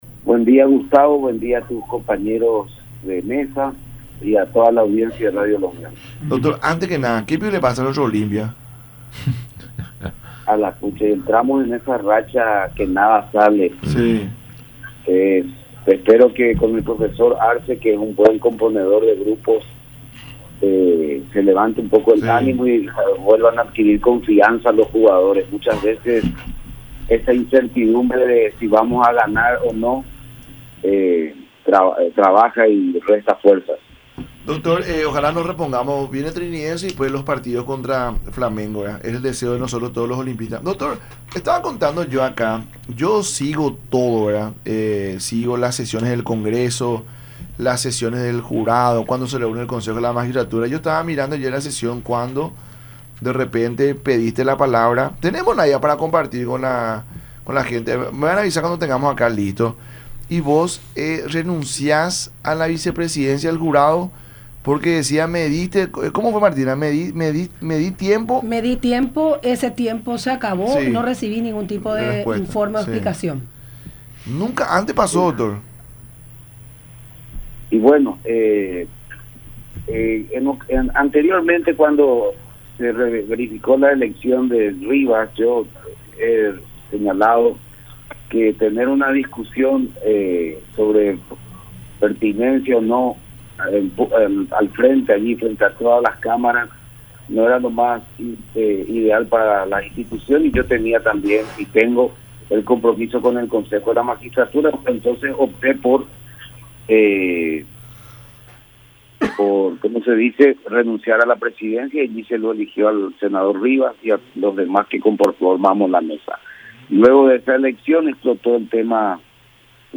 “Nuestras cuestiones personales cuando estamos en cargos públicos, trascienden y afectan la imagen institucional, el se comprometió a dar explicaciones sobre sus deudas, luego se sumaron otros hechos que denotan desprolijidades en su administración personal”, afirmó Oscar Paciello en entrevista con el programa “La Mañana De Unión” por Unión TV y radio La Unión.